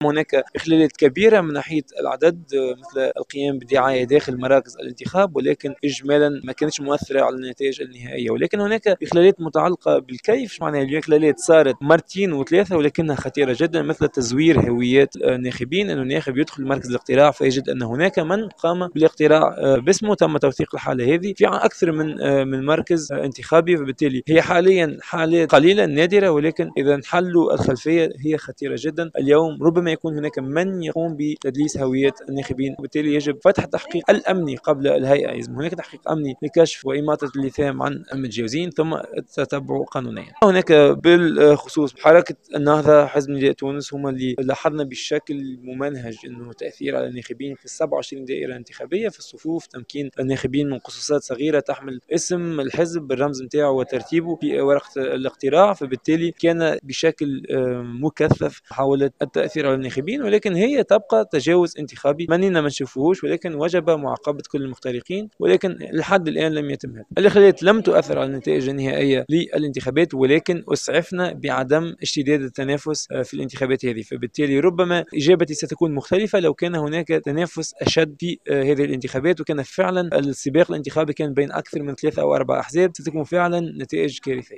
ندوة صحفية عقدت لتقديم التقرير الاولي للمنظمة عن الانتخابات التشريعية